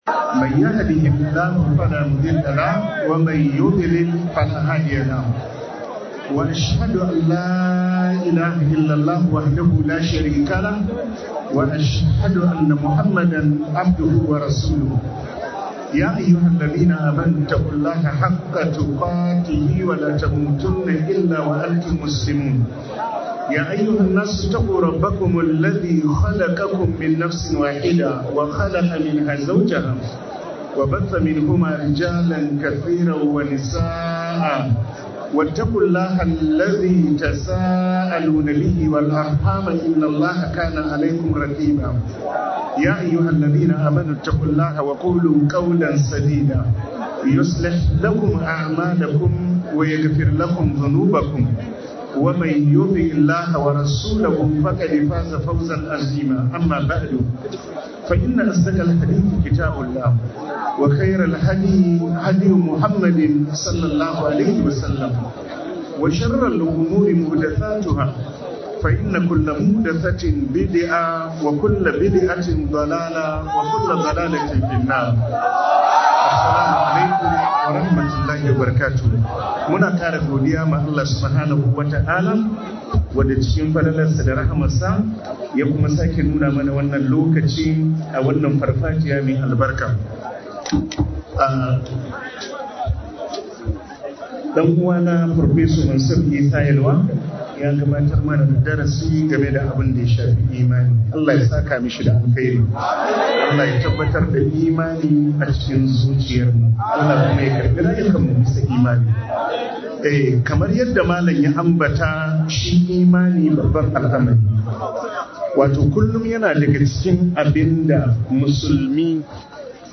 Ni'imar Musulunci da Imani - Ivory Coast - MUHADARA